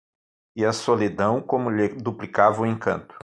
Read more Noun Verb encantar to enchant, charm to delight to bewitch, put a spell on Read more Frequency C1 Pronounced as (IPA) /ẽˈkɐ̃.tu/ Etymology Deverbal from encantar.